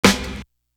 Woodchuck Snare.wav